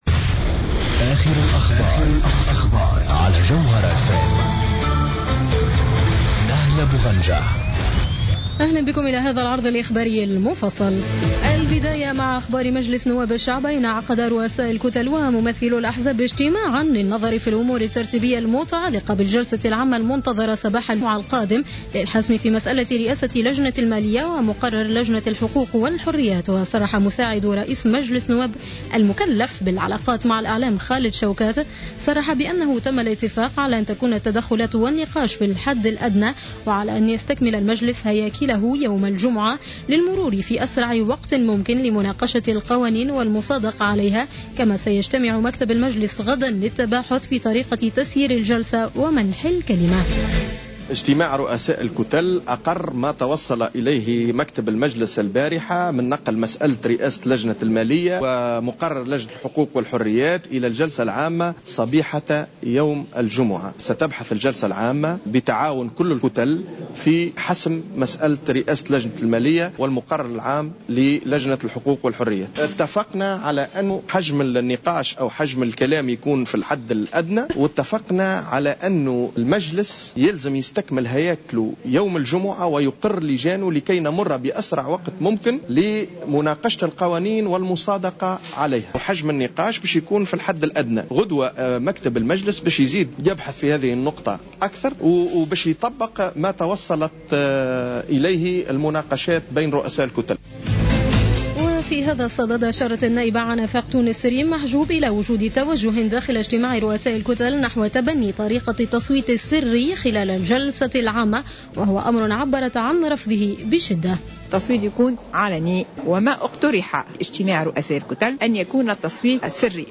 نشرة الأخبار السابعة مساء ليوم الاربعاء 25 فيفري 2015